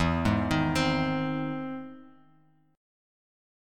E+M7 chord